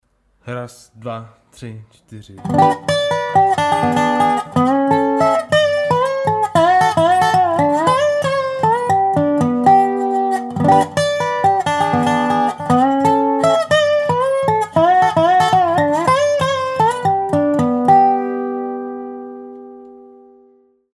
Žánr: Blues.